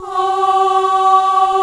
FEM 4 G3.wav